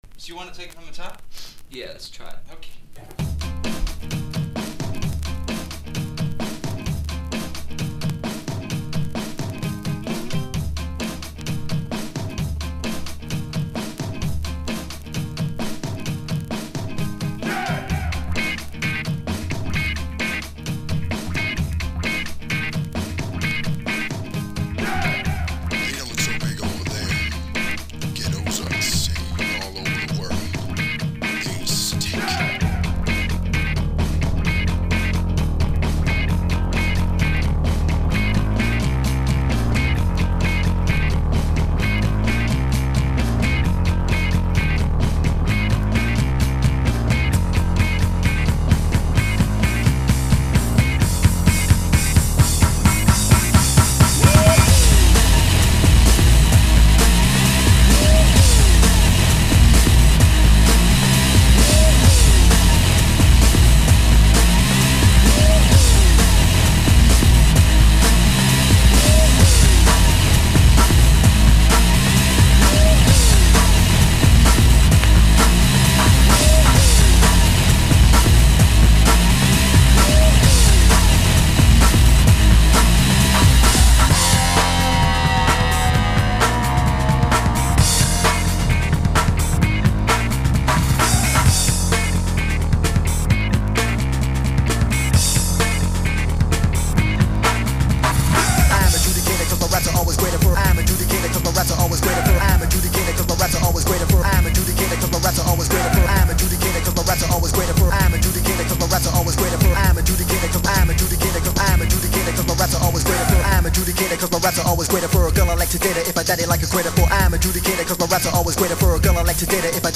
# 90’s ROCK# BREAK BEATS / BIG BEAT